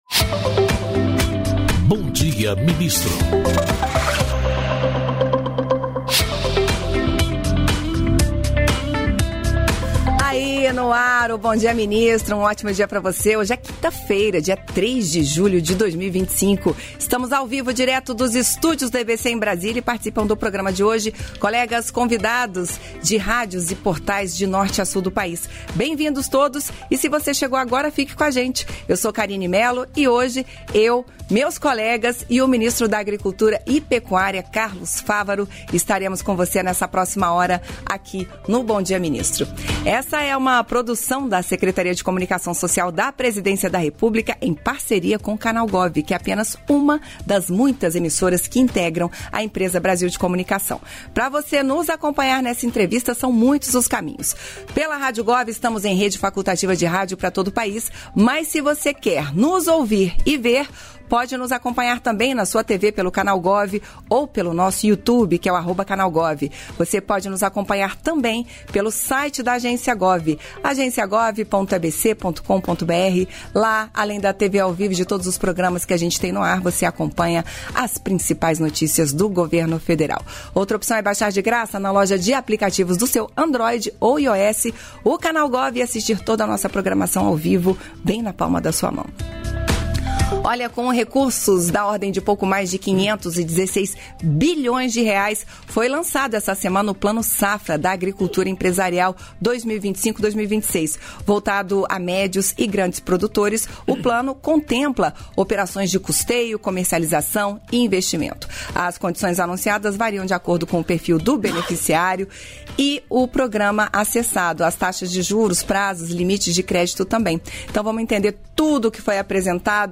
Íntegra da participação do ministro da Agricultura e Pecuária, Carlos Fávaro, no programa "Bom Dia, Ministro" desta quinta-feira (3), nos estúdios da EBC em Brasília (DF).